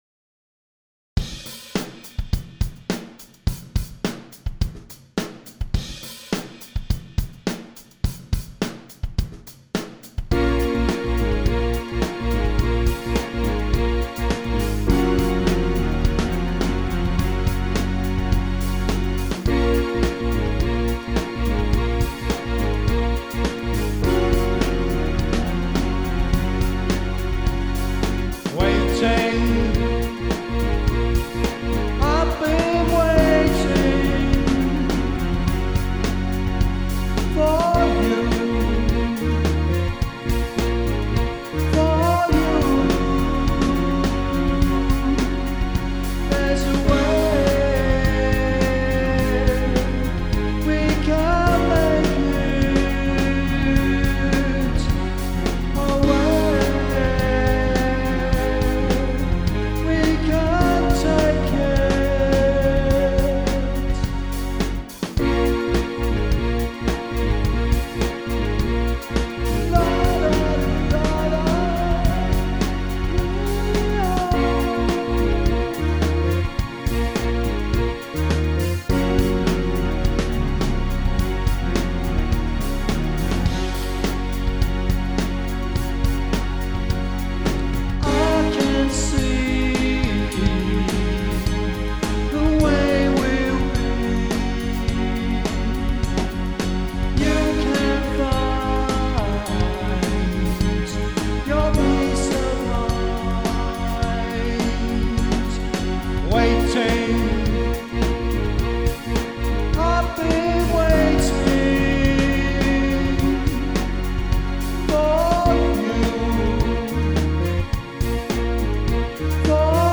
5:29/105bpm